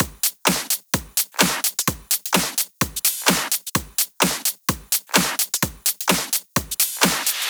VDE 128BPM Renegade Drums 2.wav